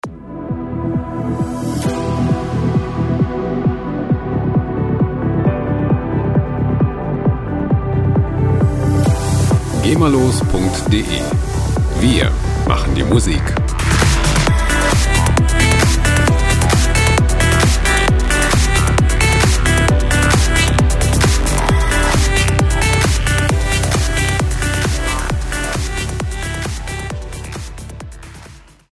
gema-freie Loops aus der Rubrik "Trance"
Musikstil: Dutch Trance
Tempo: 133 bpm